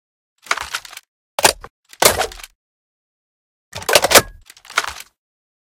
main Divergent / mods / M82 Reanimation / gamedata / sounds / weapons / librarian_m82 / reload.ogg 28 KiB (Stored with Git LFS) Raw Permalink History
reload.ogg